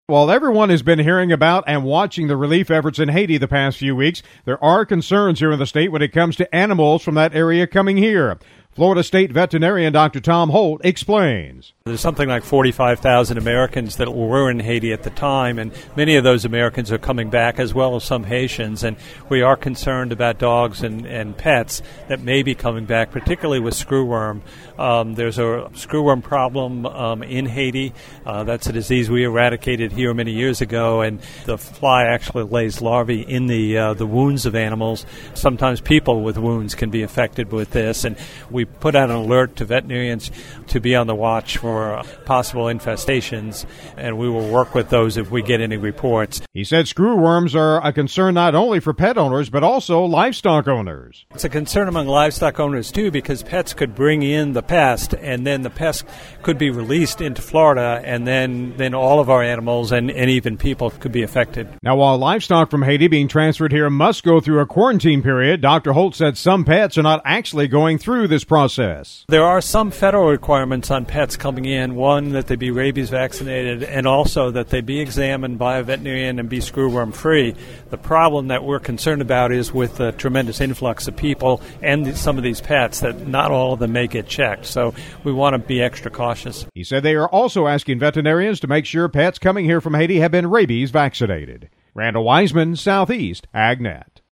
The tragedy in Haiti a few weeks ago has kept many busy with relief efforts, but Florida State Veterinarian Dr. Tom Holt says there are concerns when it comes to animals from that country coming here.